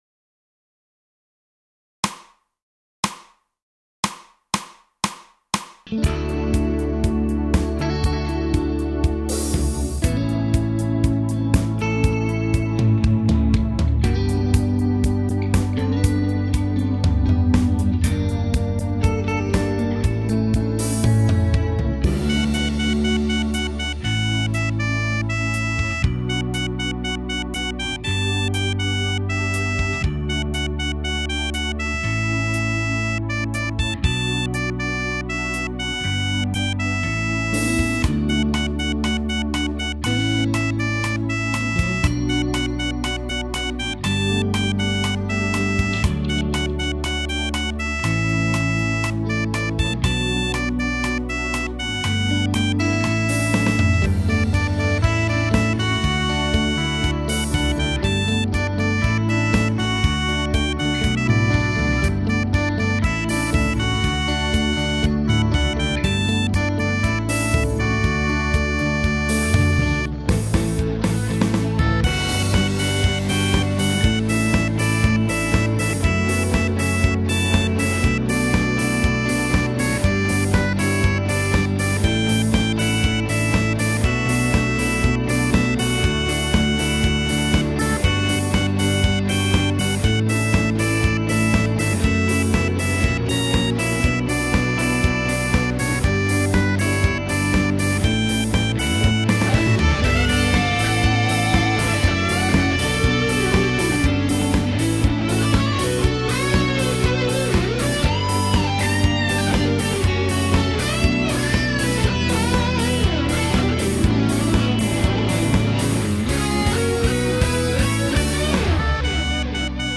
歌メロ、詩の字数を合わせたファイル（ミラー）